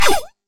Free Miss Whoosh sound effect — Combat.
Tags combat damage hit impact miss whoosh
miss-whoosh.mp3